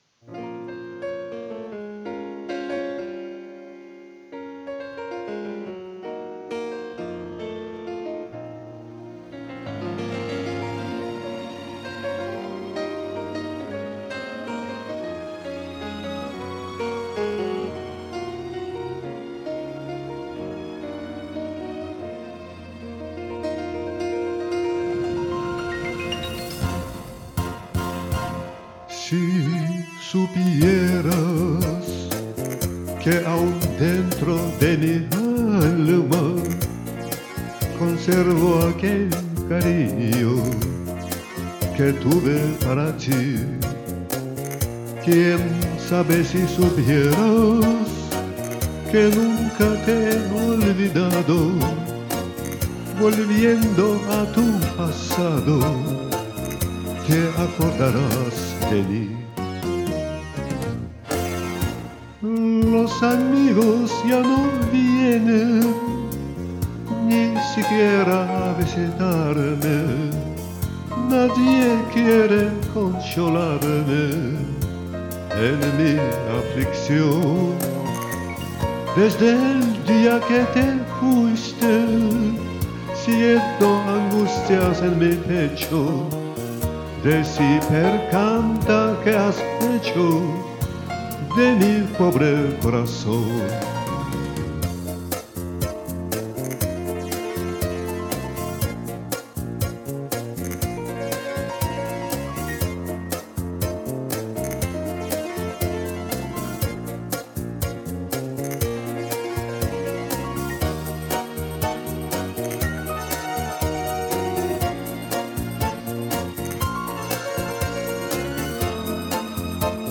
Как отрывок из радиопрограммы... из того времени...